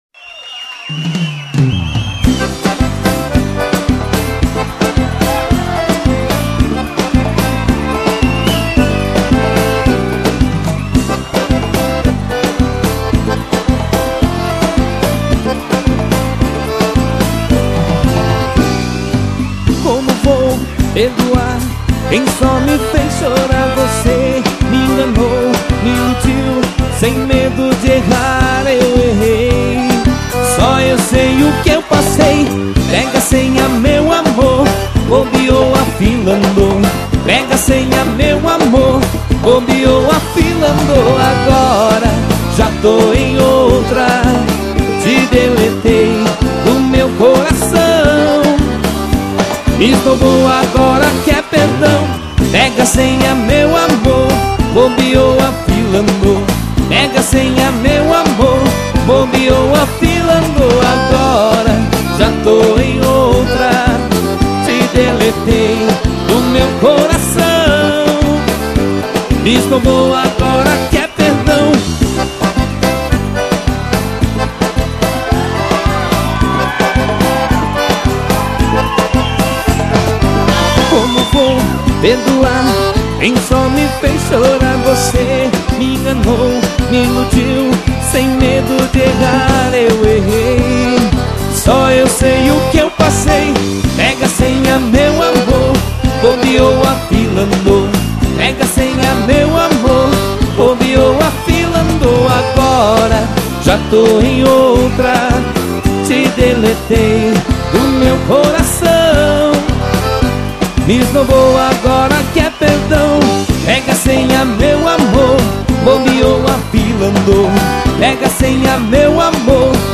EstiloSertanejo
Cidade/EstadoMaringá / PR